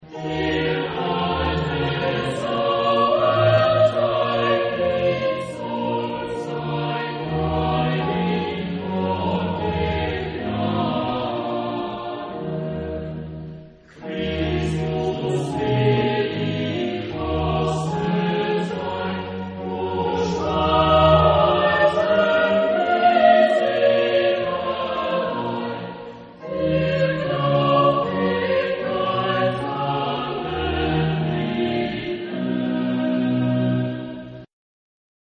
Genre-Style-Form: Sacred ; Baroque ; Cantata
Type of Choir: SATB  (4 mixed voices )
Soloist(s): SATB  (4 soloist(s))
Instrumentation: Chamber orchestra  (8 instrumental part(s))
Tonality: E minor